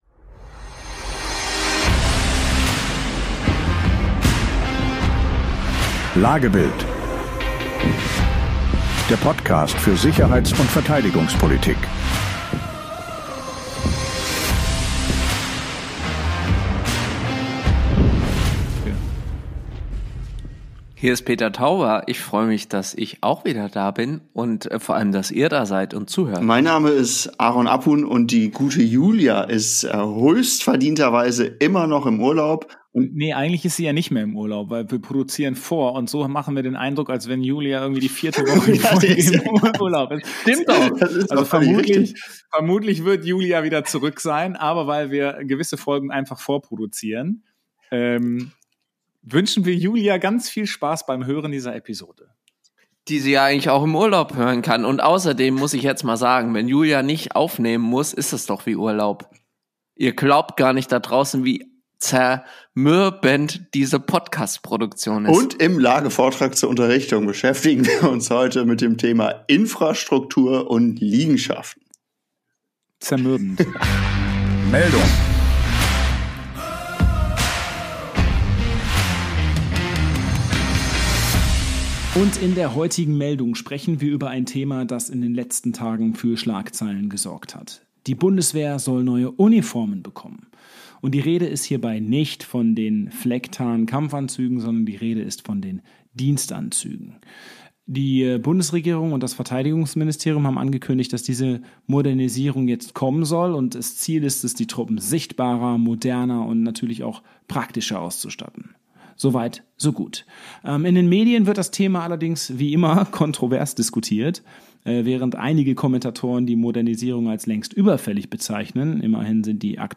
Eine lebhafte Debatte mit überraschenden Einsichten.